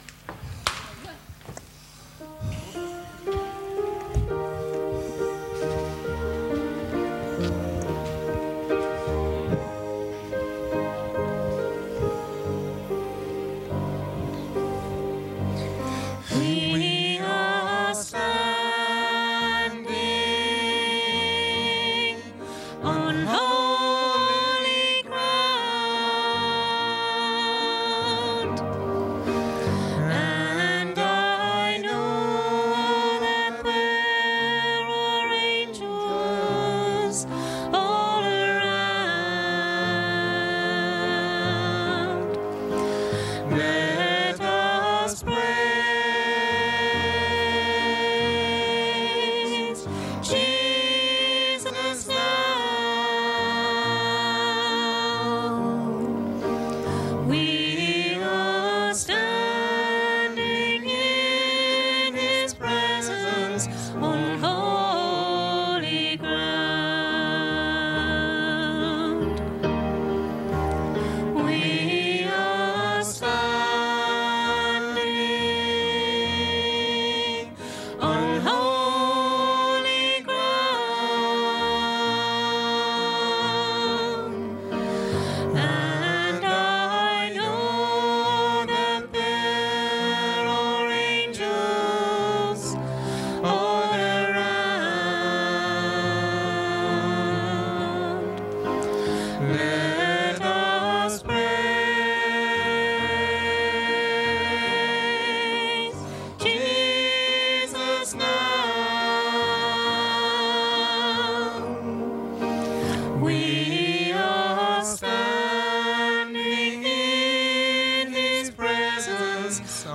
Communion service - Sittingbourne Baptist Church
Join us for our monthly evening communion service.